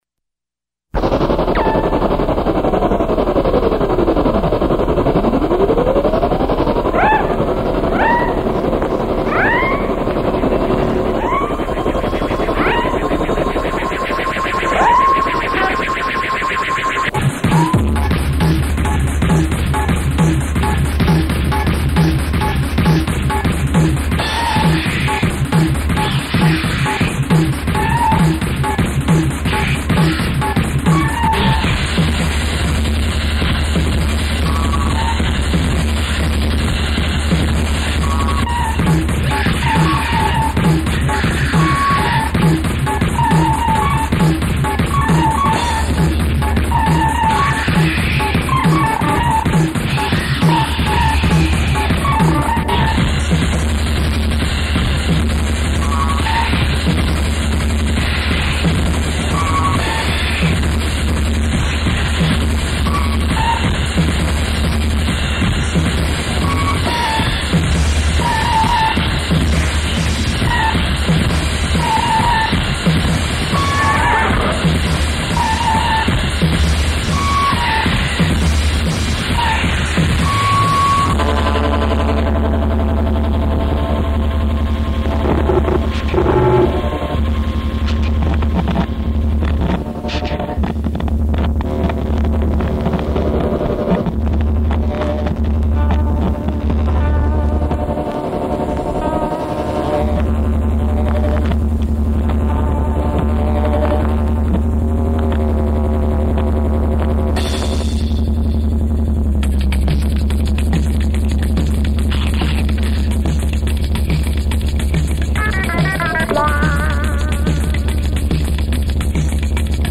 recorded by the band at Studios 509